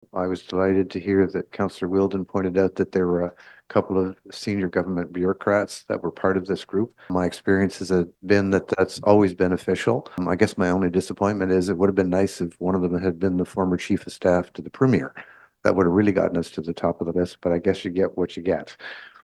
More from Councillor Faretis: